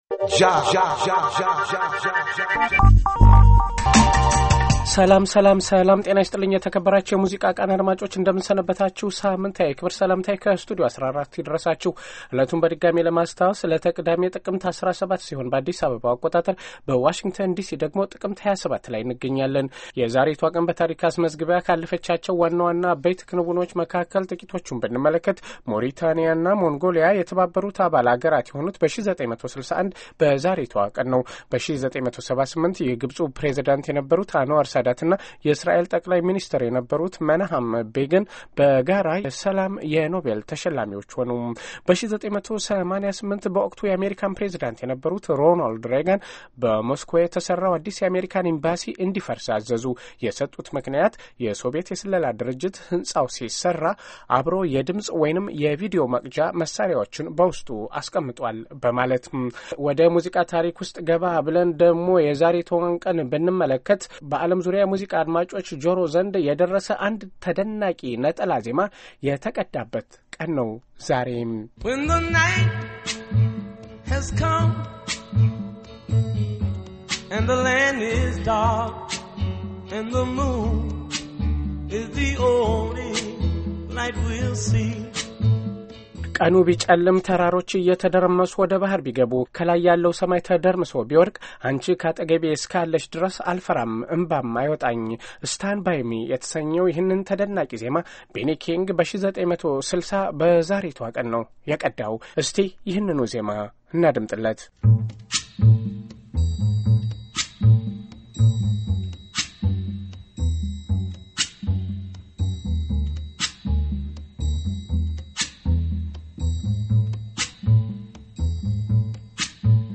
የsoul ድምፃዊ